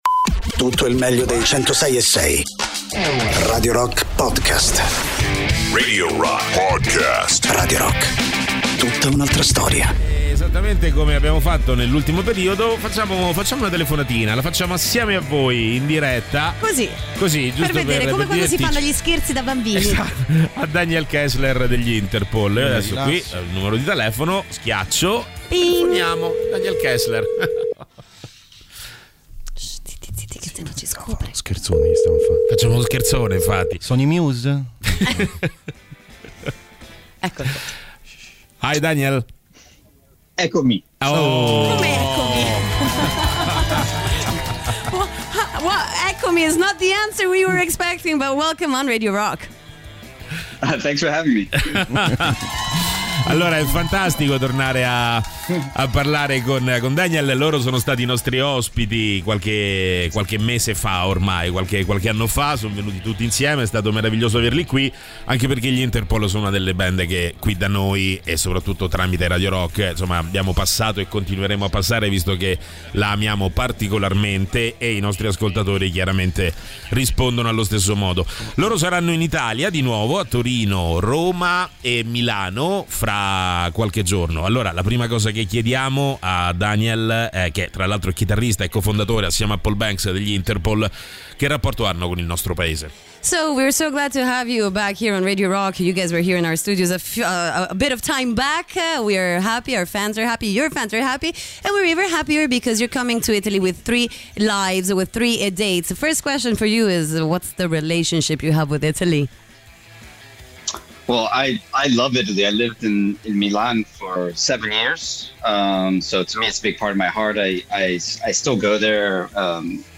Interviste: Daniel Kessler (Interpol) (01-06-23)
Daniel Kessler degli Interpol, ospite telefonico